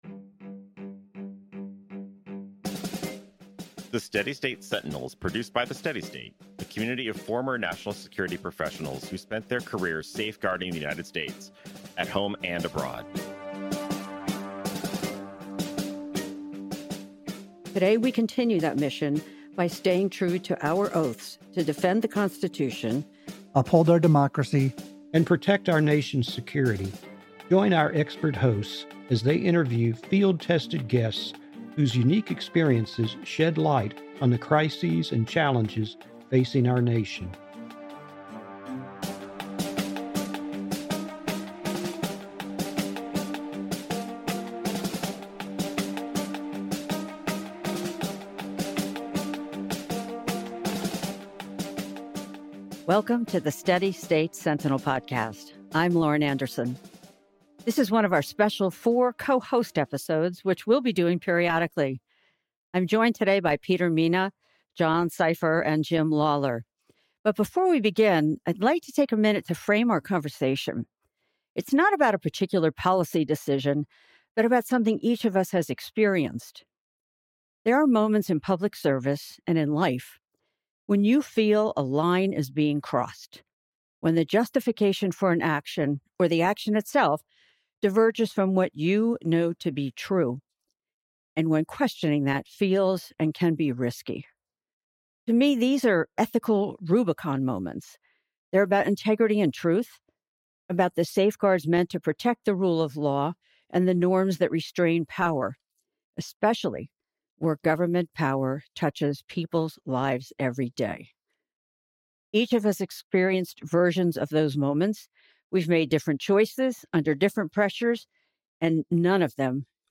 Four former national security professionals discuss ethical "Rubicon moments" when government officials face pressure to compromise integrity, including experiences during the Iraq War intelligence failures, the 2020 election letter controversy, and current threats to federal employees.